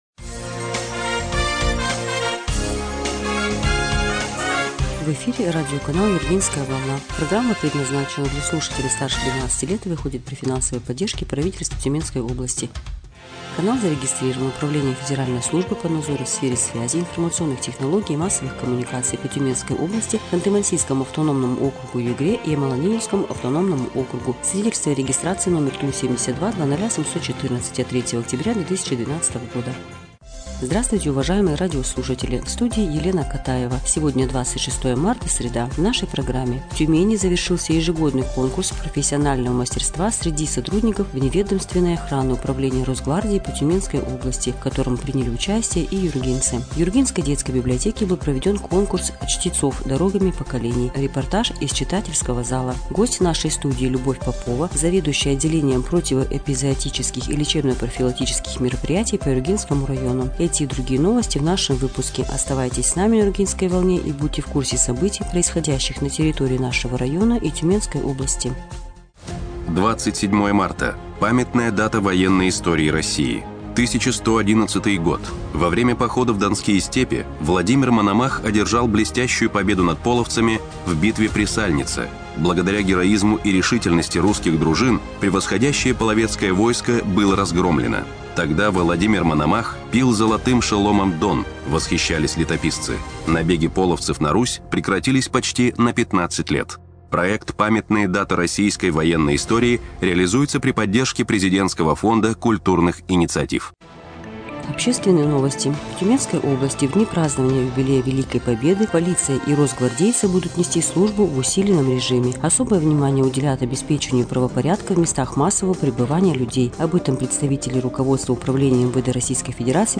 Эфир радиопрограммы "Юргинская волна" от 26 марта 2025 года